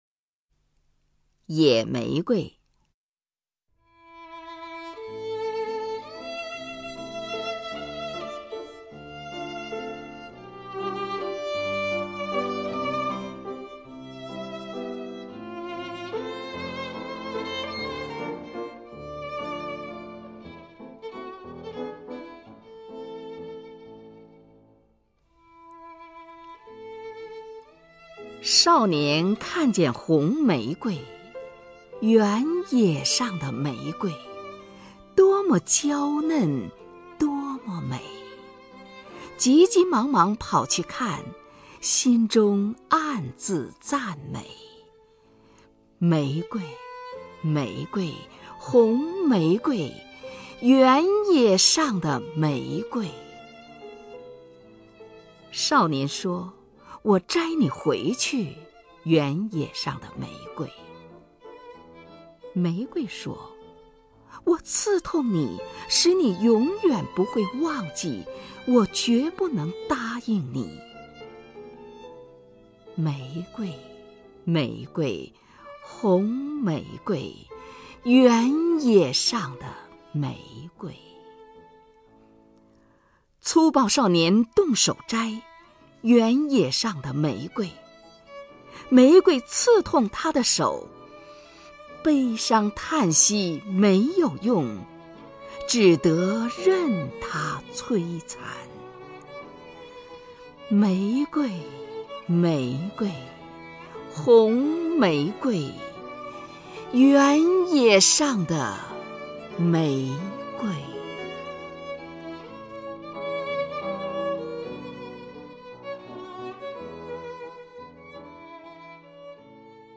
首页 视听 名家朗诵欣赏 虹云
虹云朗诵：《野玫瑰》(（德）约翰·沃尔夫冈·冯·歌德)